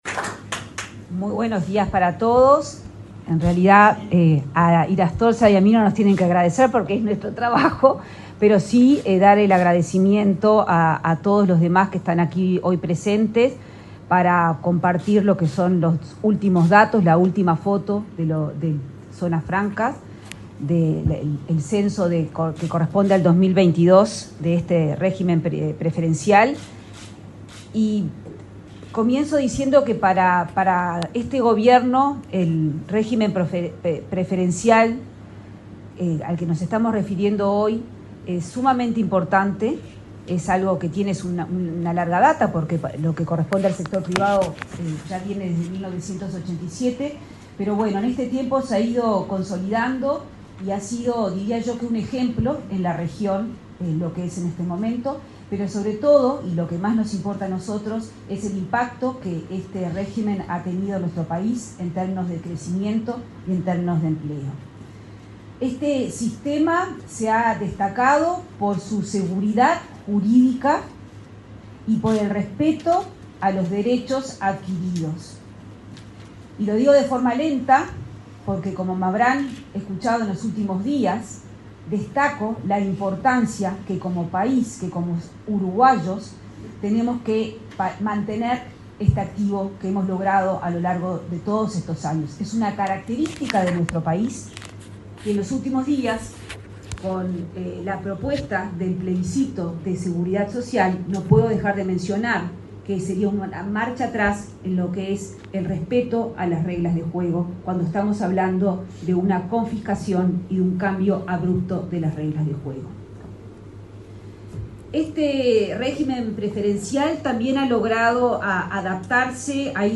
Palabras de autoridades en acto en el MEF
Palabras de autoridades en acto en el MEF 09/10/2024 Compartir Facebook X Copiar enlace WhatsApp LinkedIn Este miércoles 9 en la sede del Ministerio de Economía y Finanzas (MEF), la titular de la cartera, Azucena Arbeleche, y la directora nacional de Zonas Francas, Ana Alfie, expusieron durante la presentación de datos del censo realizado por esa dependencia.